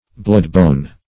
Bladebone \Blade"bone`\ (-b[=o]n`)